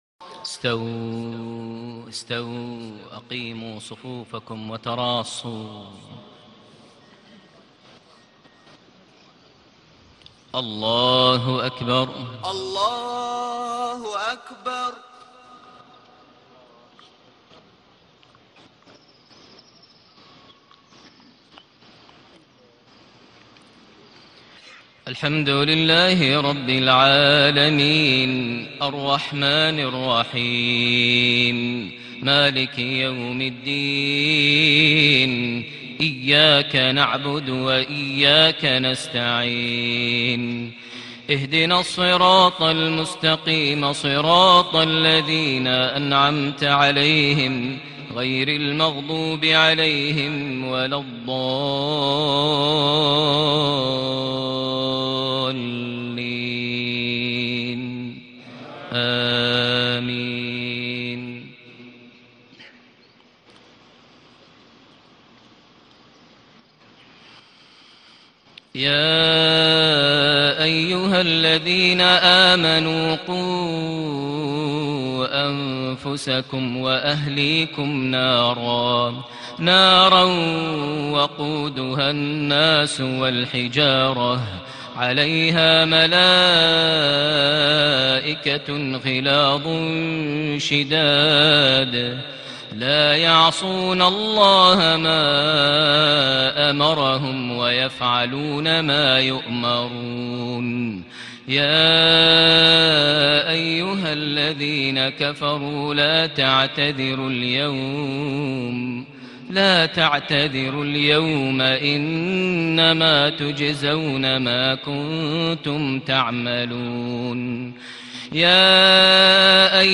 صلاة المغرب ٢٨ صفر ١٤٣٨هـ سورة التحريم ٦-١٢ > 1438 هـ > الفروض - تلاوات ماهر المعيقلي